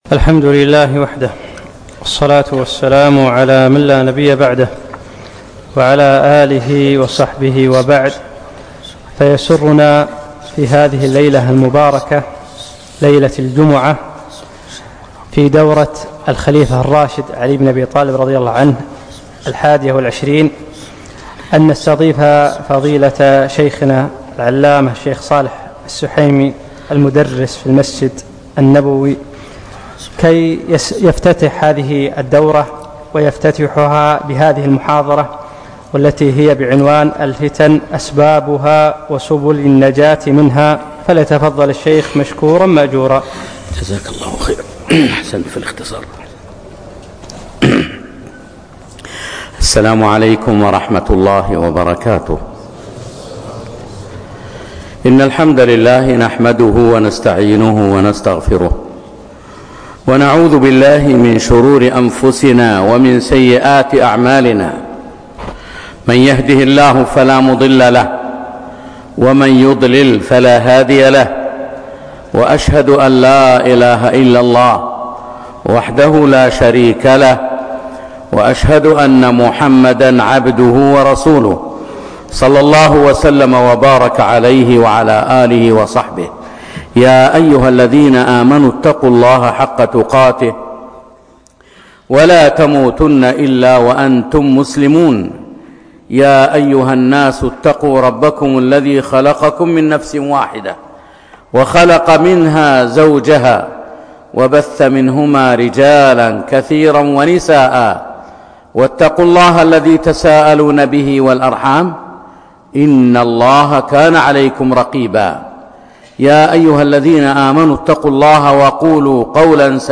الفتن وأسبابها وسبل النجاة منها - المحاضرة الإفتتاحية في دورة الخليفة الراشد علي بن أبي طالب